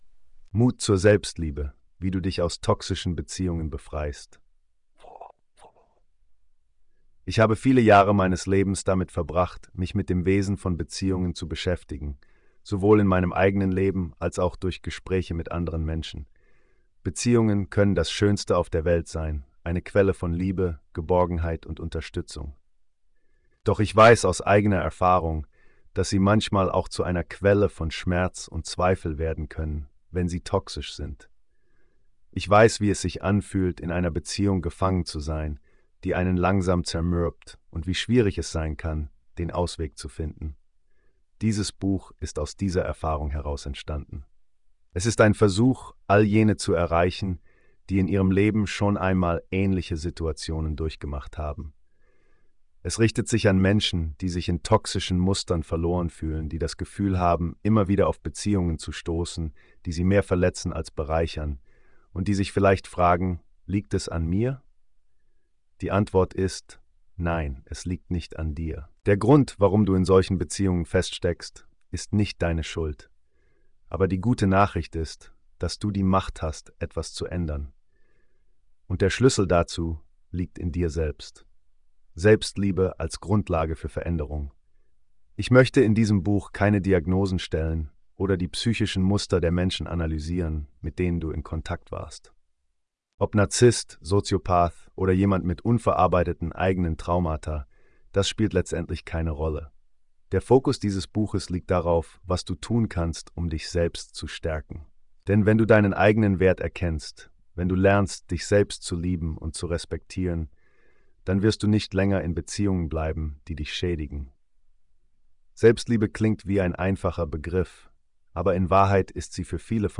Hör dir die Audio-Version dieses Artikels an (generiert von KI).